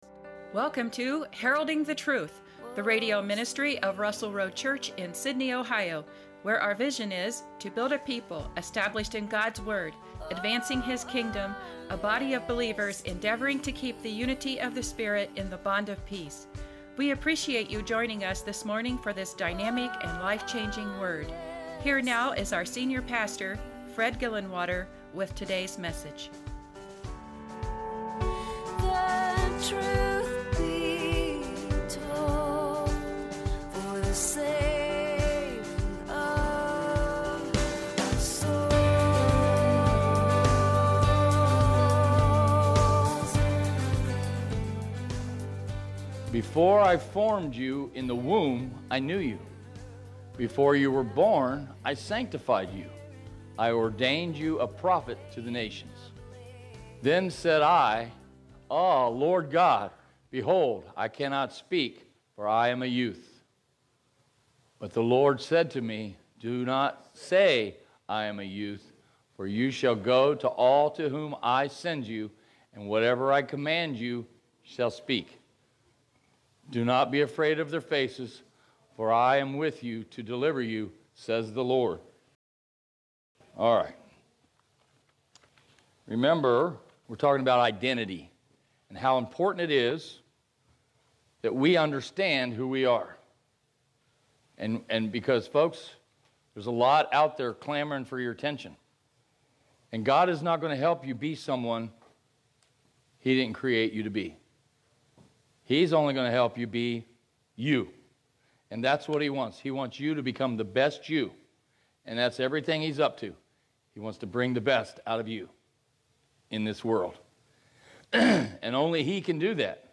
Sermons | Russell Road Church